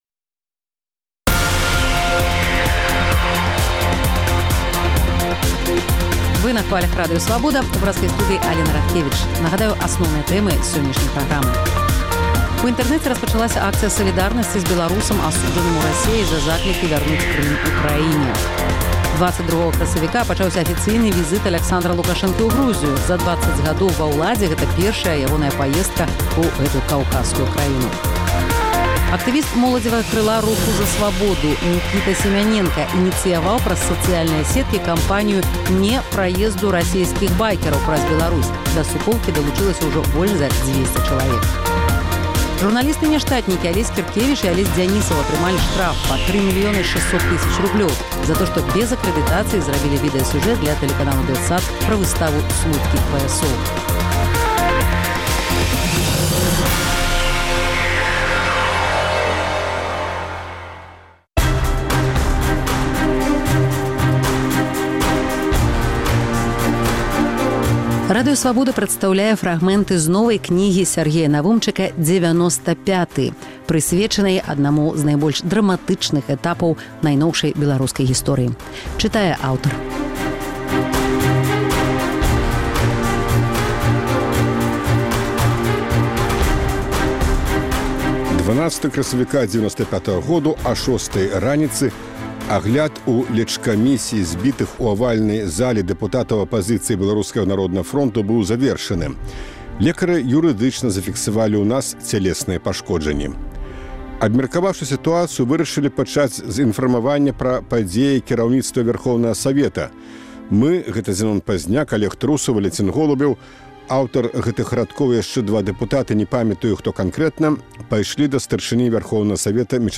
Радыё Свабода працягвае чытаць фрагмэнты новай кнігі Сяргея Навумчыка «Дзевяноста пяты». У гэтым разьдзеле — пра рэакцыю кіраўніцтва Вярхоўнага Савету і Генэральнай пракуратуры на зьбіцьцё дэпутатаў апазыцыі БНФ.